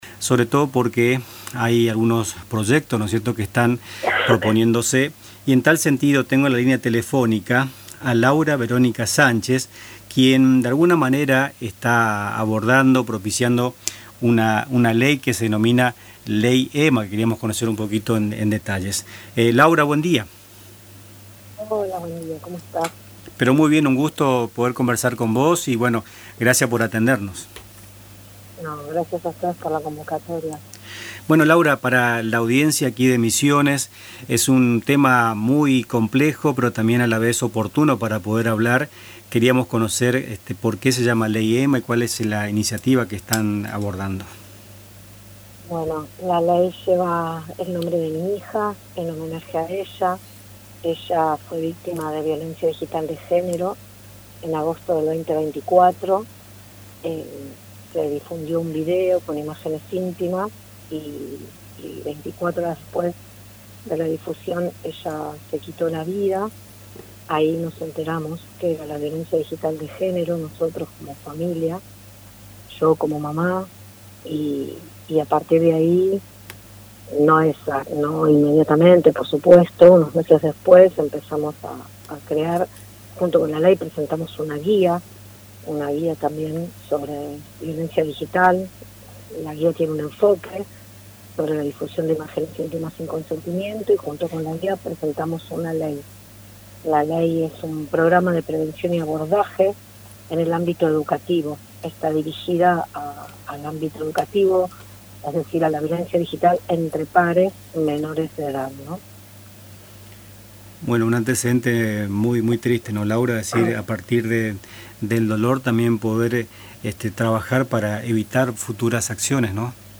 En una entrevista exclusiva en el programa Nuestras Mañanas